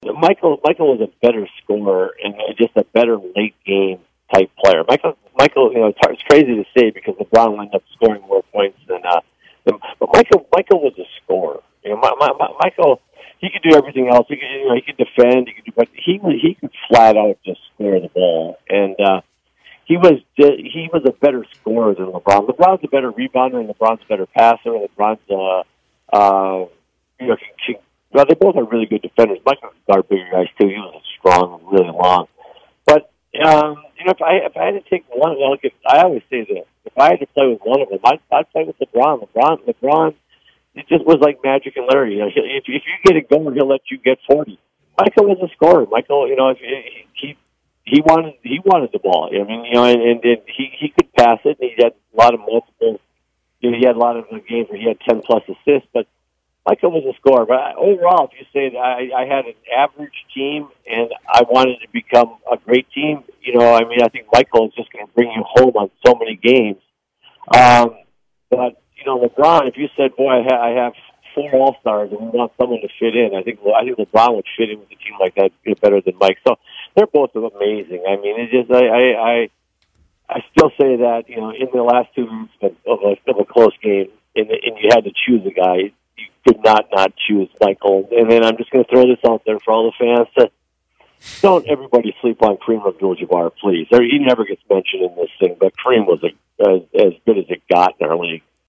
Kevin Mchale joined The Usual Suspects. He gave his thoughts on how good Lebron is when it comes down to the greats in the NBA.